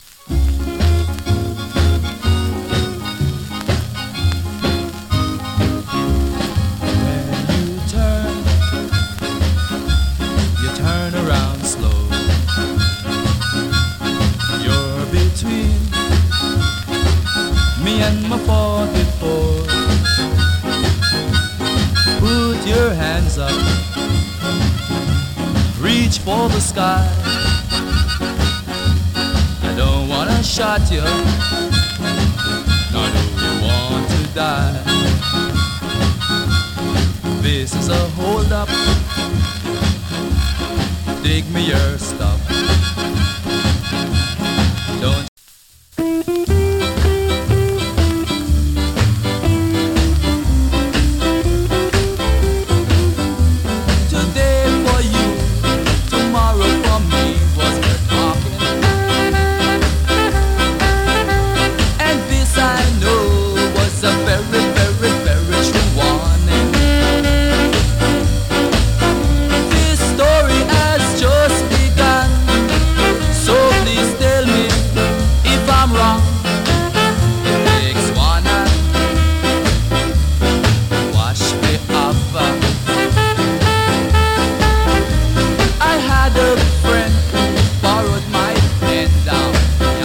チリ、ジリノイズ少々有り。
NICE VOCAL EARLY SKA !